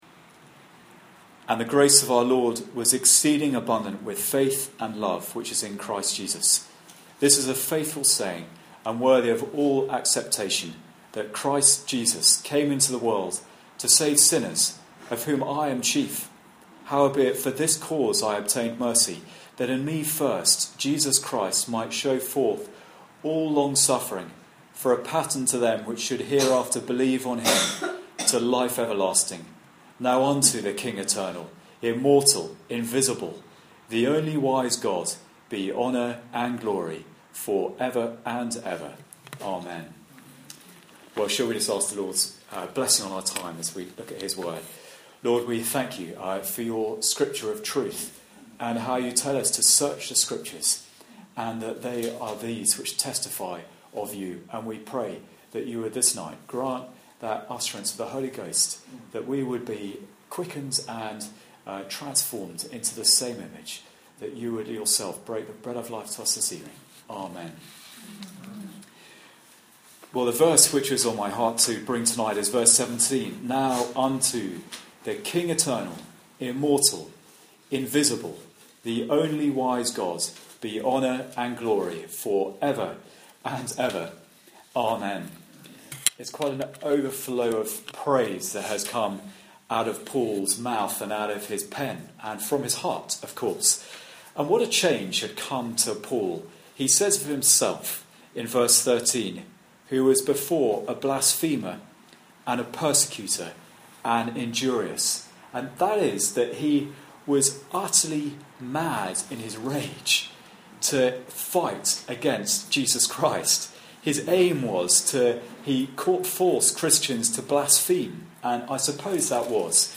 Service Type: Sunday Evening Service